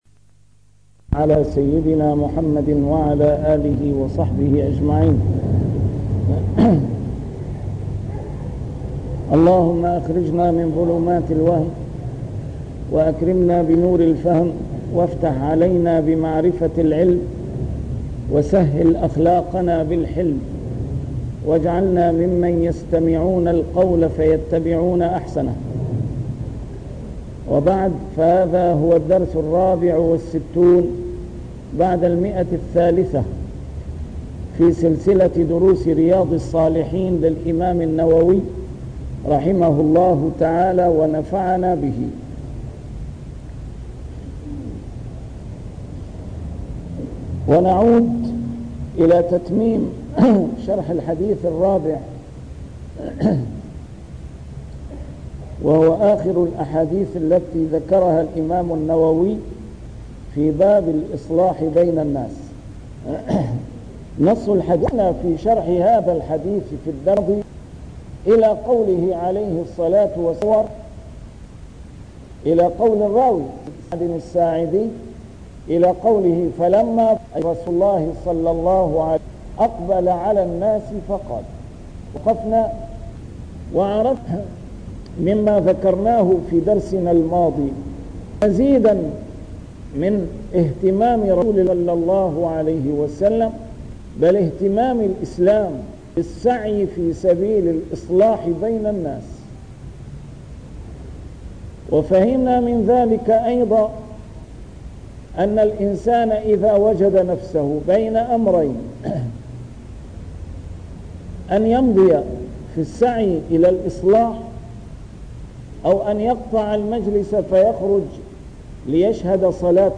A MARTYR SCHOLAR: IMAM MUHAMMAD SAEED RAMADAN AL-BOUTI - الدروس العلمية - شرح كتاب رياض الصالحين - 364- شرح رياض الصالحين: الإصلاح بين الناس فضل ضعفة المسلمين